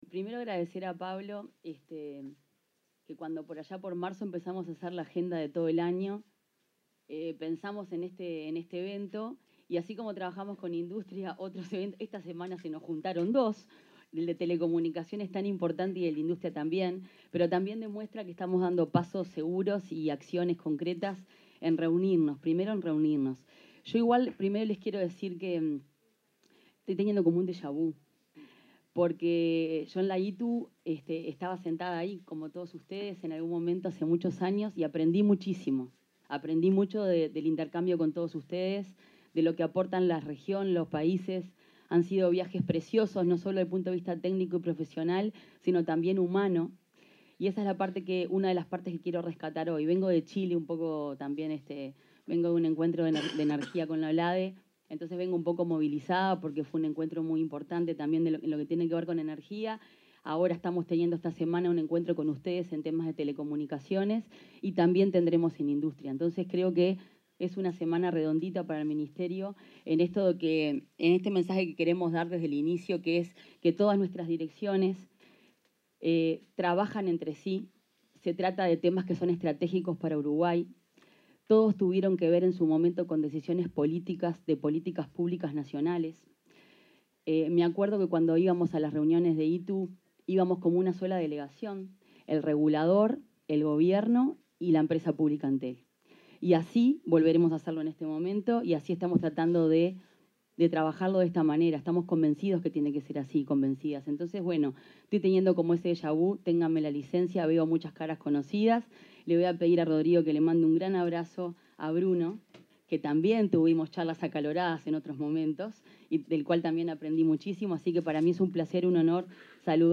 Palabras de la ministra de Industria, Fernanda Cardona
Palabras de la ministra de Industria, Fernanda Cardona 06/10/2025 Compartir Facebook X Copiar enlace WhatsApp LinkedIn En la apertura del Coloquio de Política y Economía de las Telecomunicaciones para la Región de las Américas, se expresó la ministra de Industria, Energía y Minería, Fernanda Cardona.